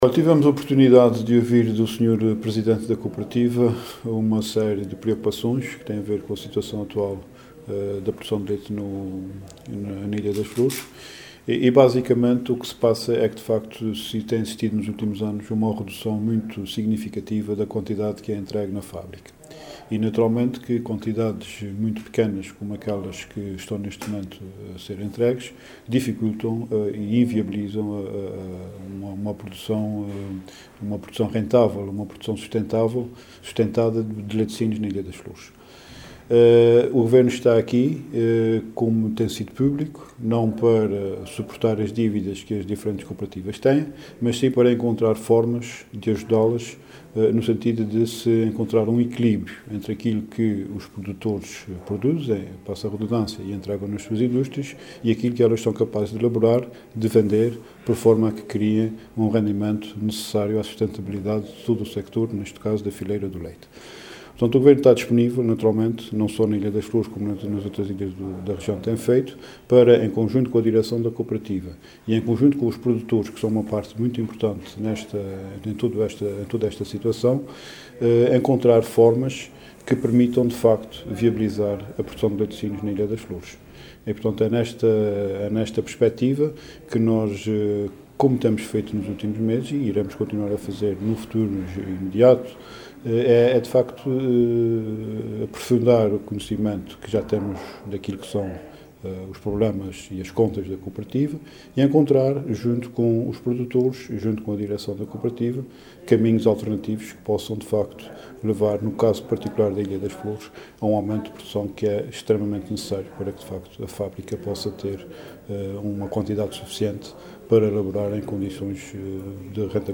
“Como temos feito nos últimos meses e iremos continuar a fazer”, disse Luís Neto Viveiros, em declarações aos jornalistas após uma reunião de trabalho com a Direção da Cooperativa Ocidental, pretende-se encontrar “caminhos alternativos que possam, no caso particular da ilha das Flores, permitir um aumento de produção, que é extremamente importante para que a fábrica possa […] laborar em condições de rentabilidade”.